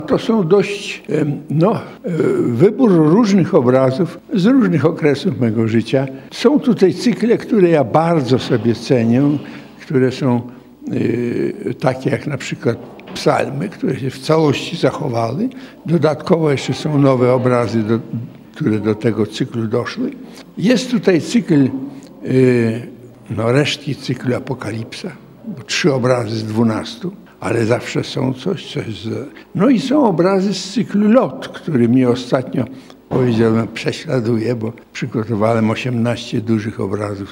Otwarcie wystawy „Andrzej Strumiłło Malarstwo” odbyło się w środę (21.06) w Centrum Sztuki Współczesnej, Galerii Andrzeja Strumiłły w Suwałkach.
O odczuciach jakie towarzyszyły wernisażowi opowiedział autor obrazów, profesor Andrzej Strumiłło.